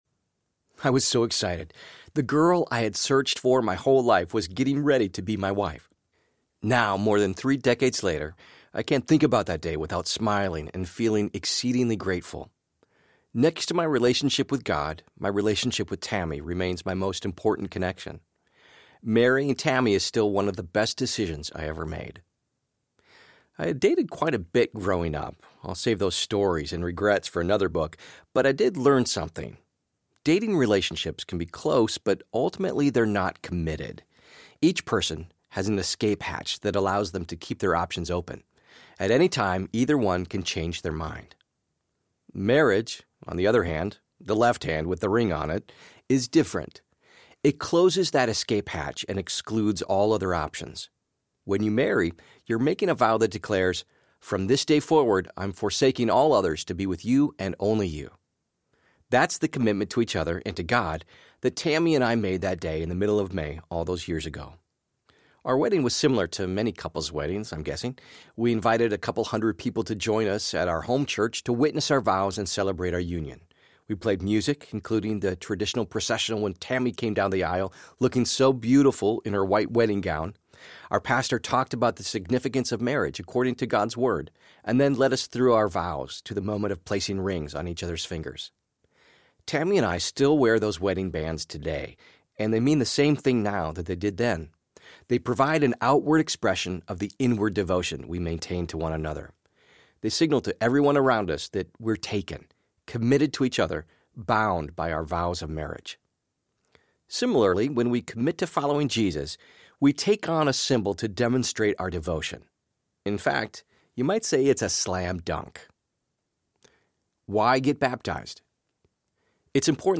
What’s Next? Audiobook
Narrator